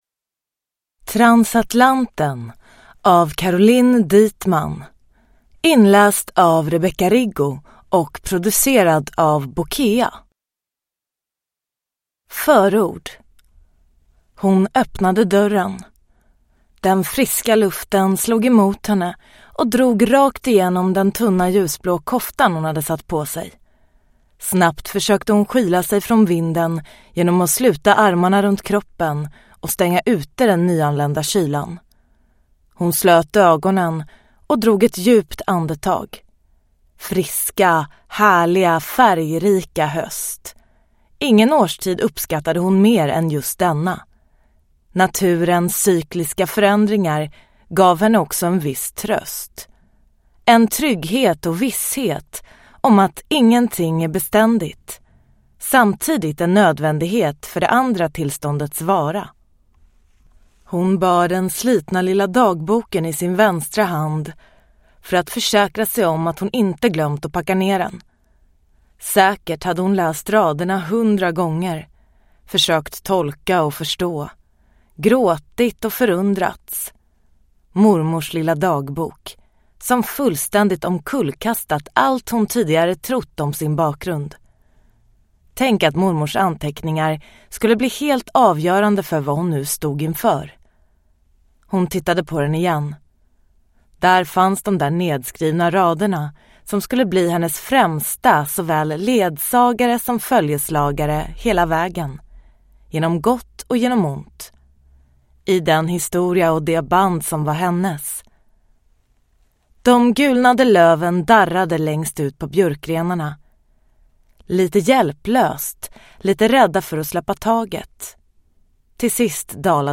Transatlanten – Ljudbok